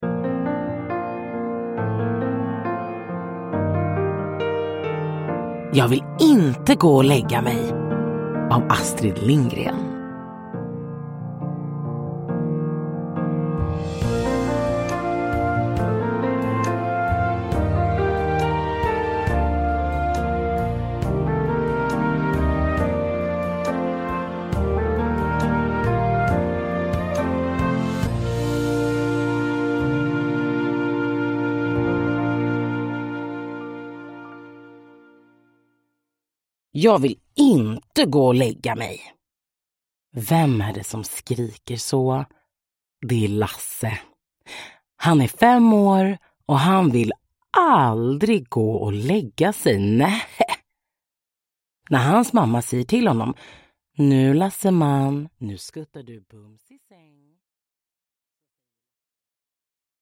Jag vill inte gå och lägga mig! – Ljudbok